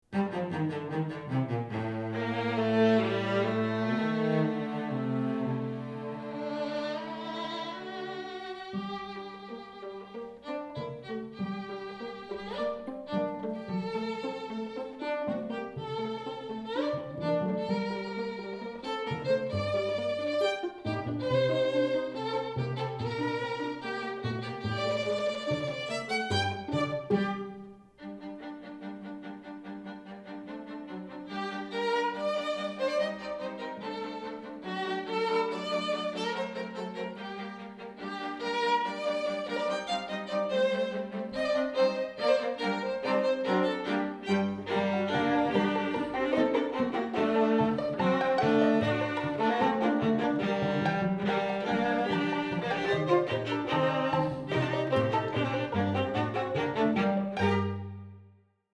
Quatuor
Musique tsigane